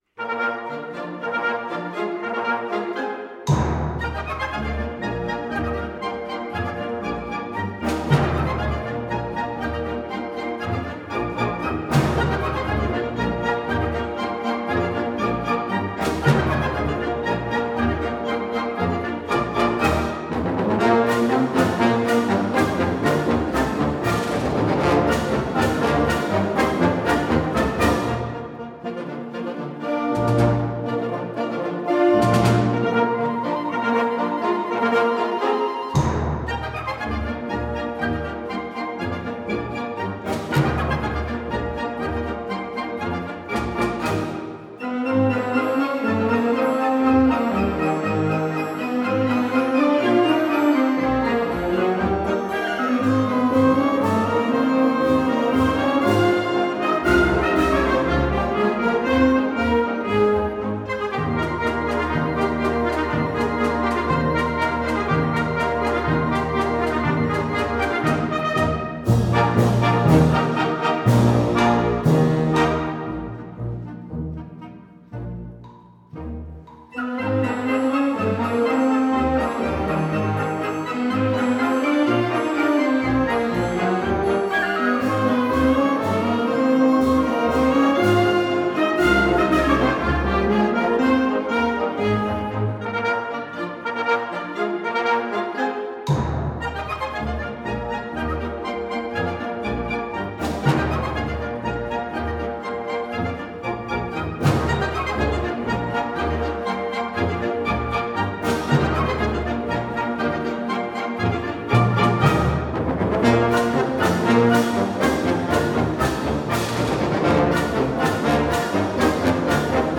Gattung: Konzertpolka
Besetzung: Blasorchester